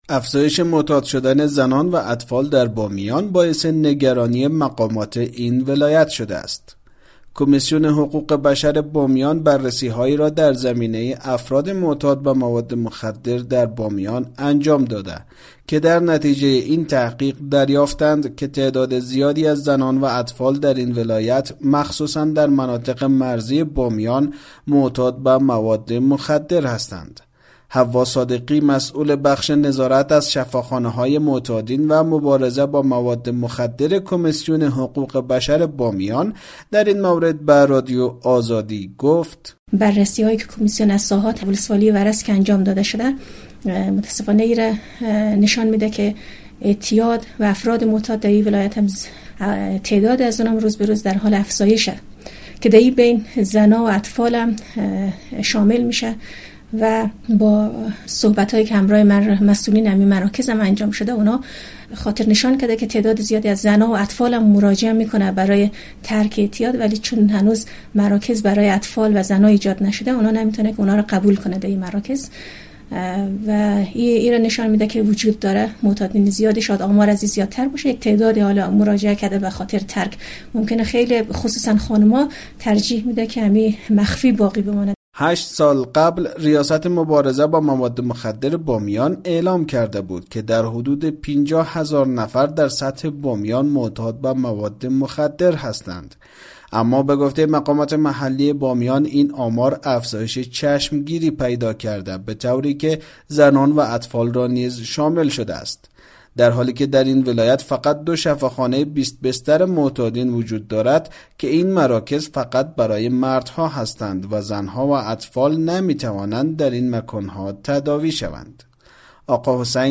فیچر کاروان زهر